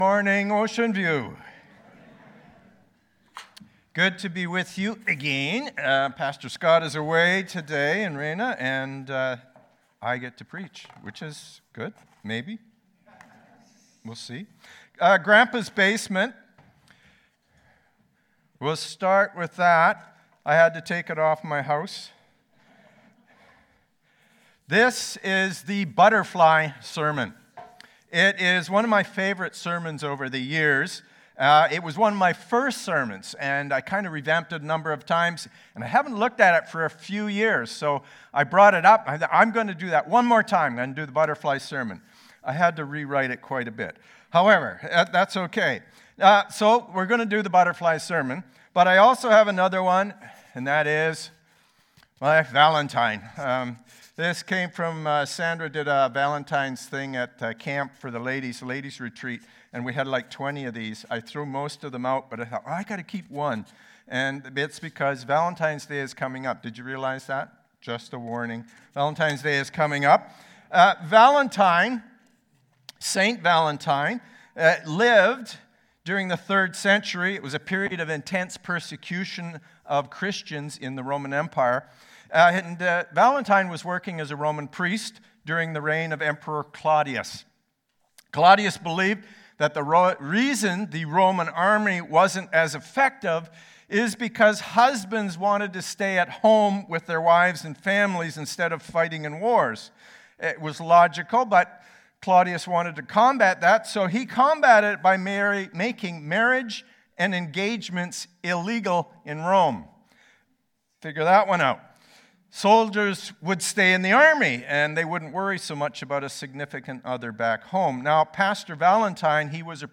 Sermons | Oceanview Community Church